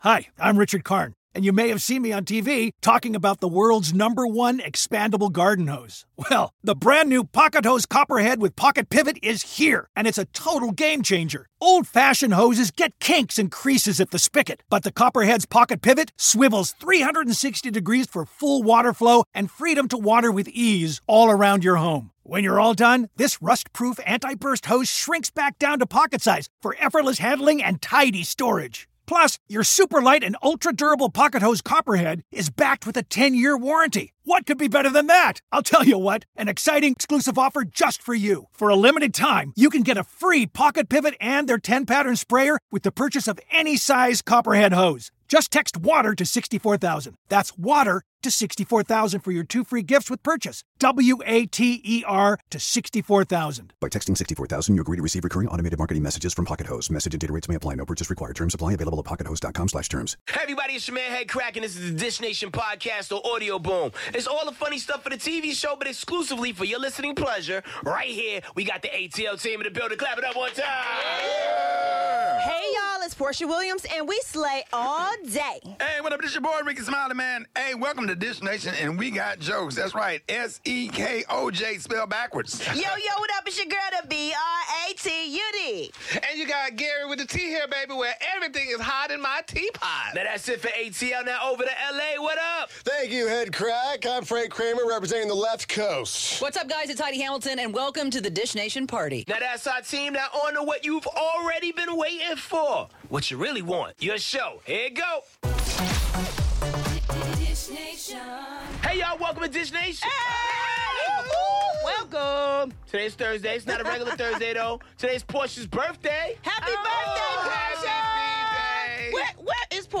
Guest co-host: Monie Love. We dish with Alison Brie and the cast of the new Netflix show 'Glow' and Will Ferrell just revealed how much of a diva Mariah Carey can really be. Plus the latest on Tom Cruise, Rihanna, Mariah Carey, Will Ferrell, Gabby Sidibe, Harry Styles, Kate Beckinsale, Helen Mirren and the messy ongoing 'Bachelor in Paradise' drama.